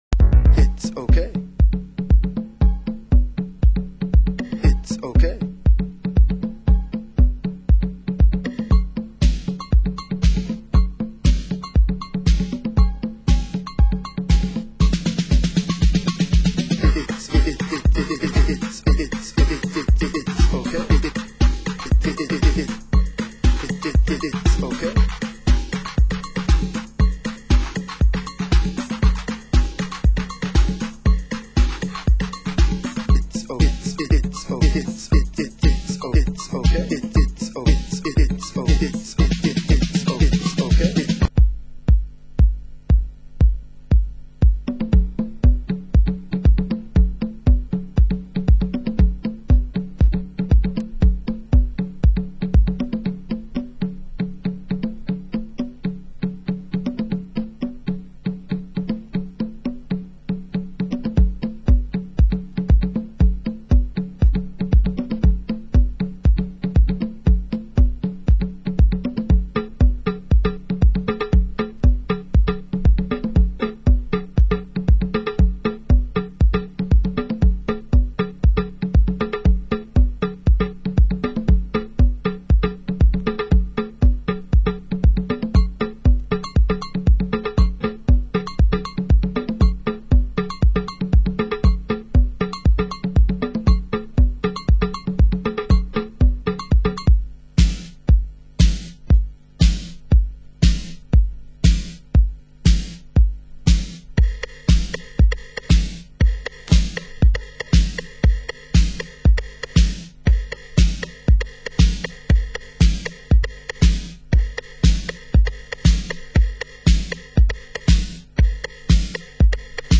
Genre: Chicago House